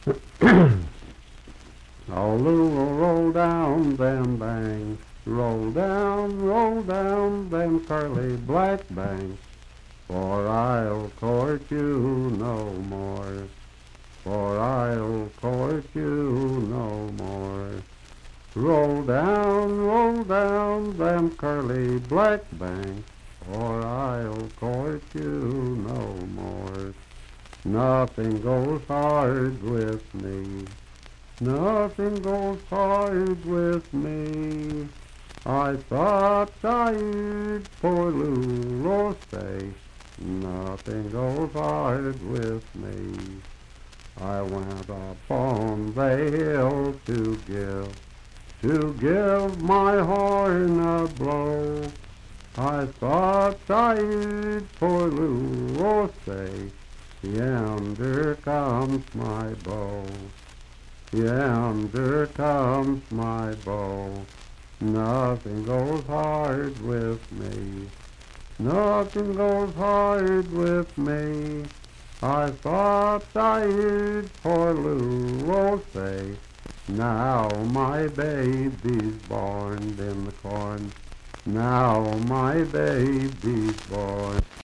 Unaccompanied vocal music
Verse-refrain 4(4-6).
Bawdy Songs
Voice (sung)
Fairview (Marion County, W. Va.), Marion County (W. Va.)